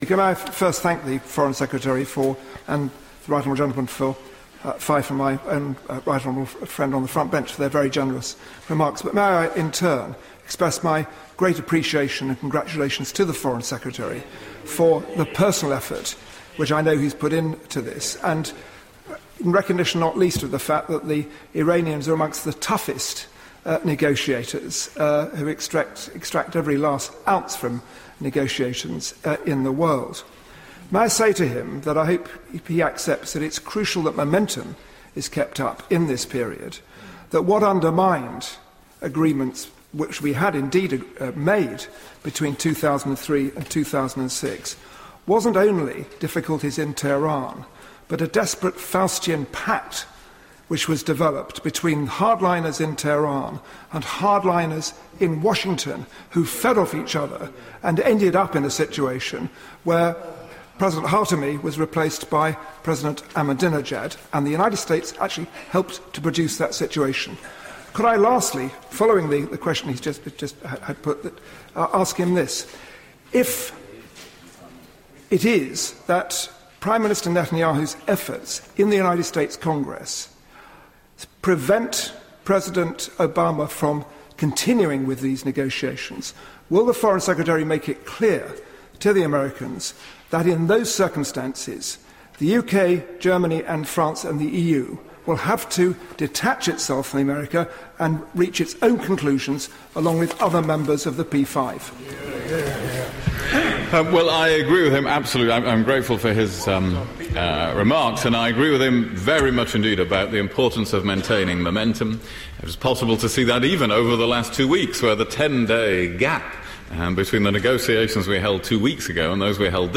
House of Commons, 25 November 2013